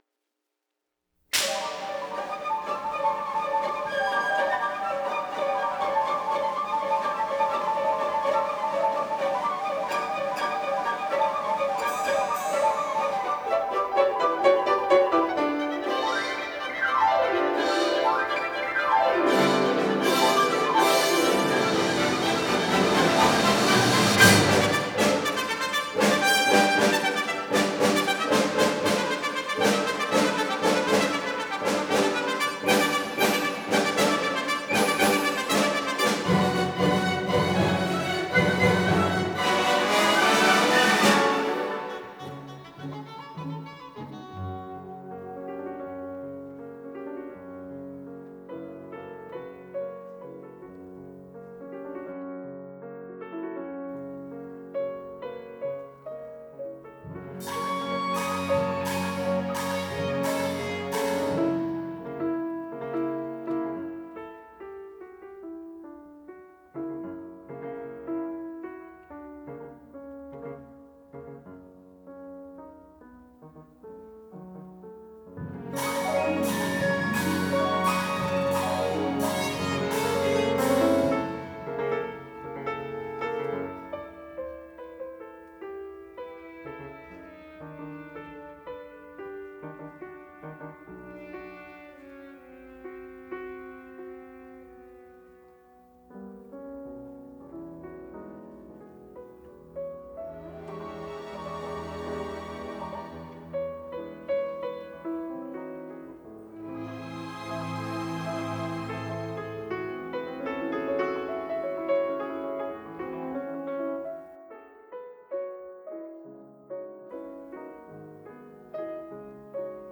urbane, modernist concerto